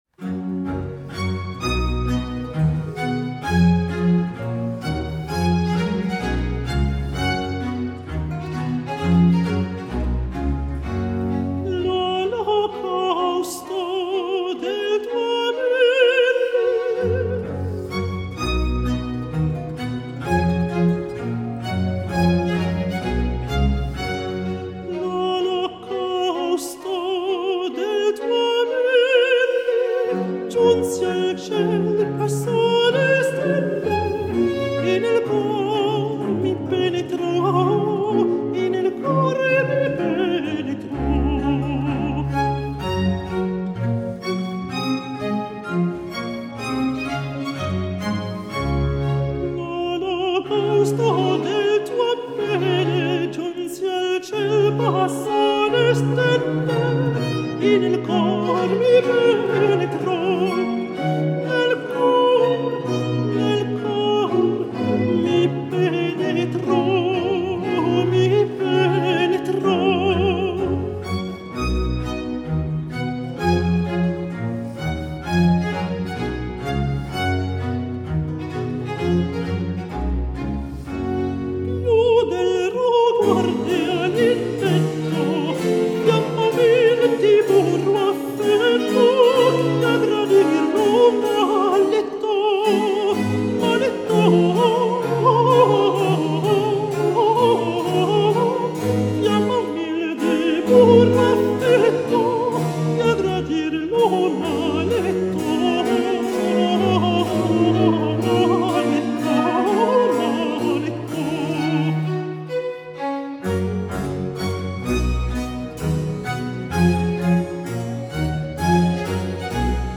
Italian baroques cantatas